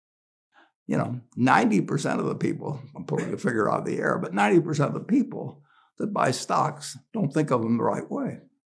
アメリカ人男性ナレーション
ハイインパクトなストーリーテリングと企業ブランディングのためにデザインされた、響き渡るプロフェッショナルなアメリカ人男性ナレーションで注目を集めましょう。
テキスト読み上げ
アメリカンアクセント
男性ボーカル
権威的で信頼できるトーン